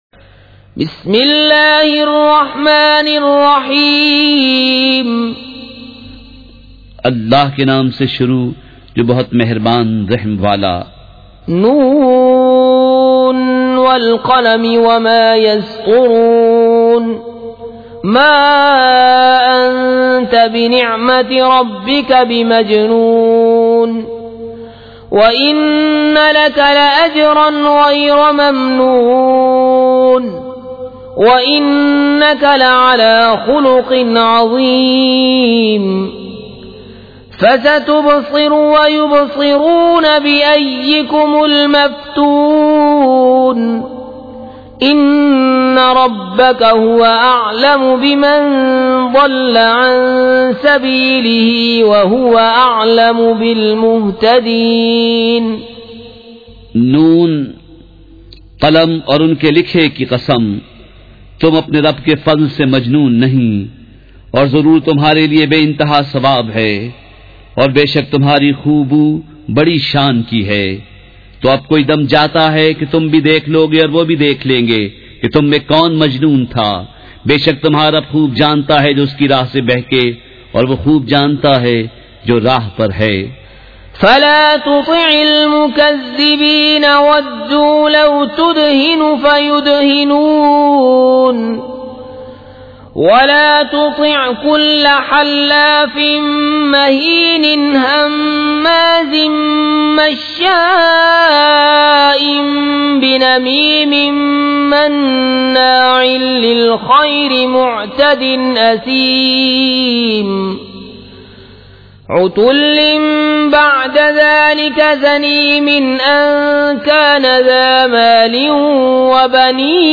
سورۃ القلم مع ترجمہ کنزالایمان ZiaeTaiba Audio میڈیا کی معلومات نام سورۃ القلم مع ترجمہ کنزالایمان موضوع تلاوت آواز دیگر زبان عربی کل نتائج 1976 قسم آڈیو ڈاؤن لوڈ MP 3 ڈاؤن لوڈ MP 4 متعلقہ تجویزوآراء